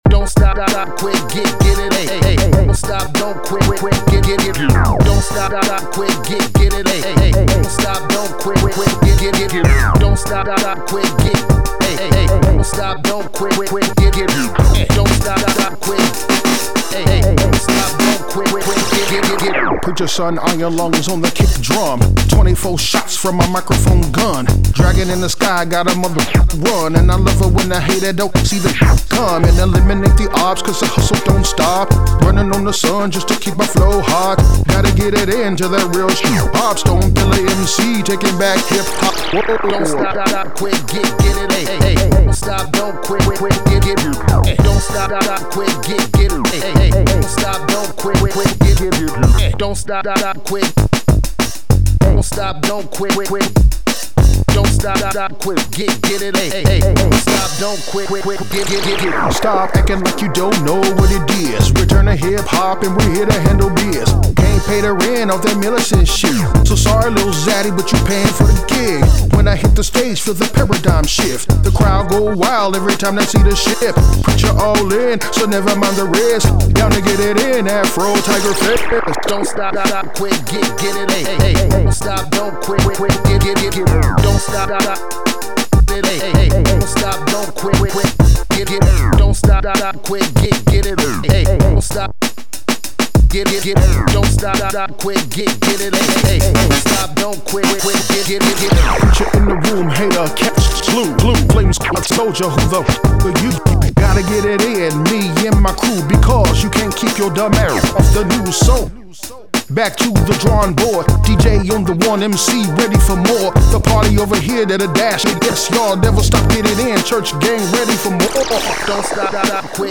Hiphop
hip hop vibes